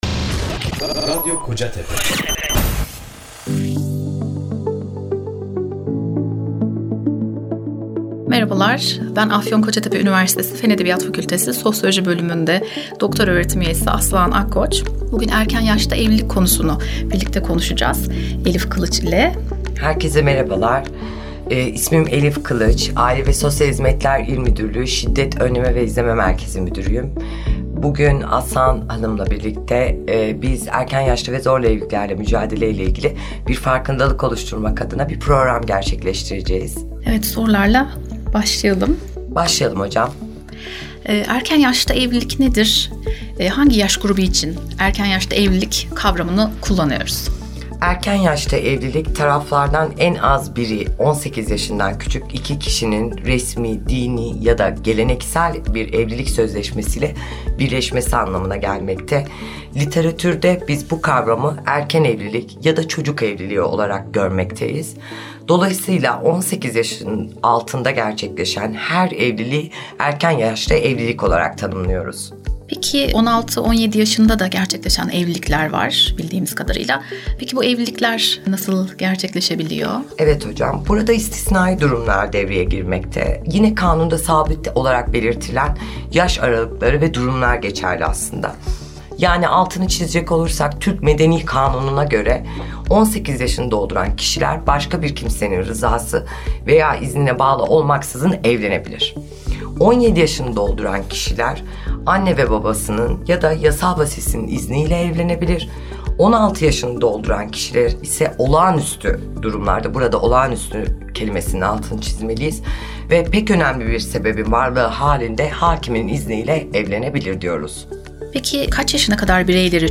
radyo söyleşi programı